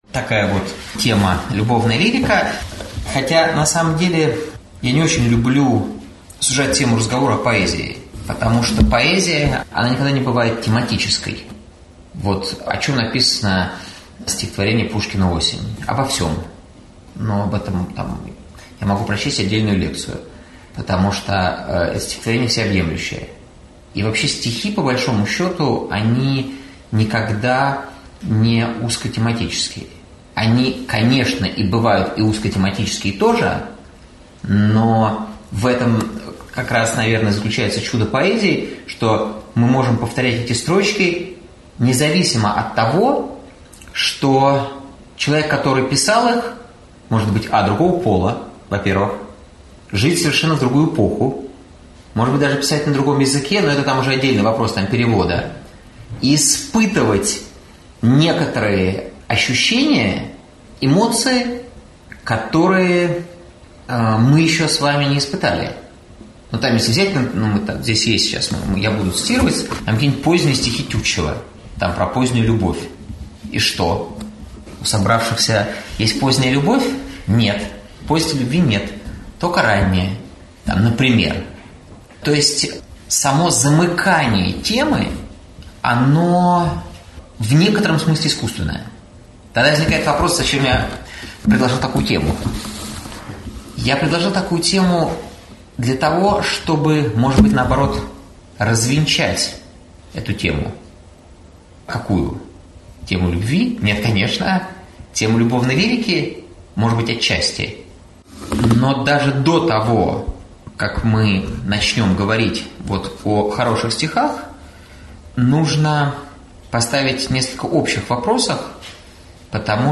Аудиокнига Я вас любил. Шедевры любовной лирики.